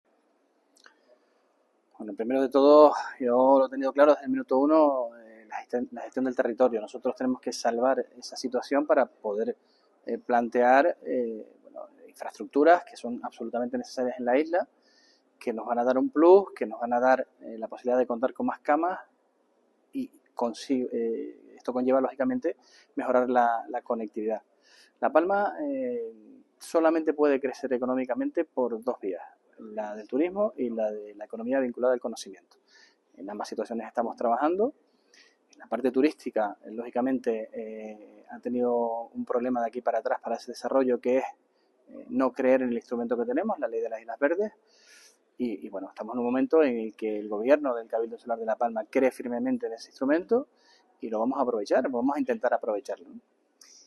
Sergio Rodríguez participó en la apertura de la segunda jornada ‘La Palma ante su espejo’, donde trasladó a las personas presentes las políticas que en esta materia está ejecutando el Cabildo insular.